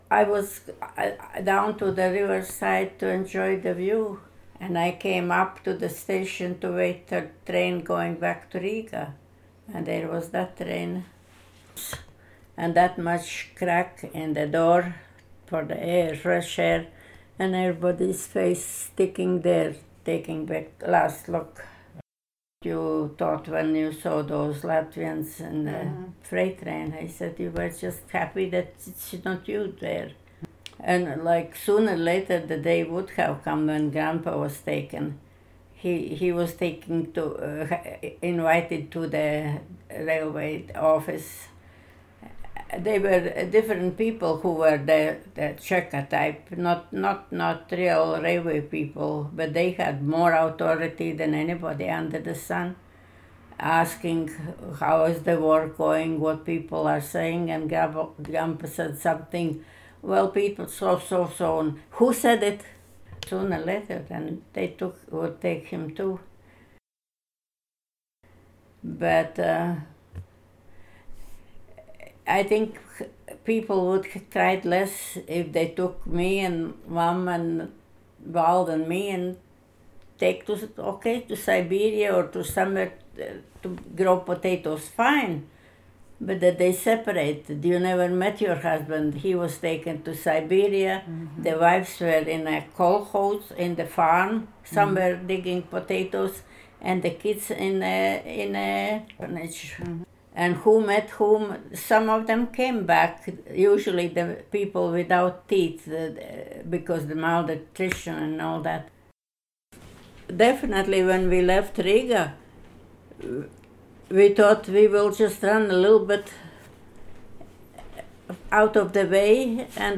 Interviewer